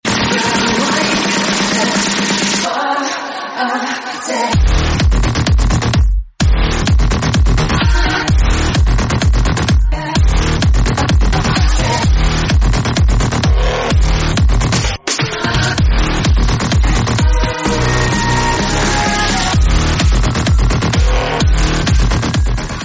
Kategorie Efekty Dźwiękowe